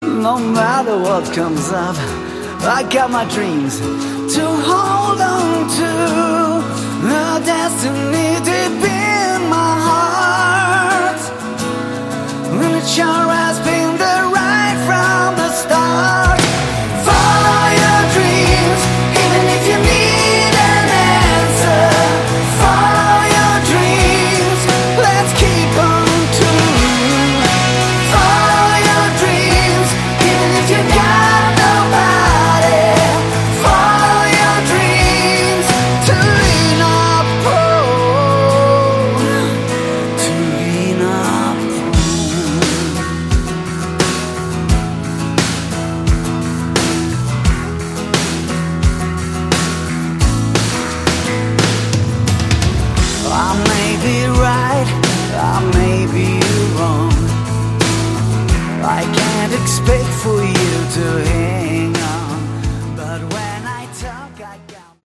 Category: Melodic Rock
lead vocals
drums, backing vocals
bass, backing vocals
lead guitar, backing vocals
keyboards, backing vocals
nice mid tempo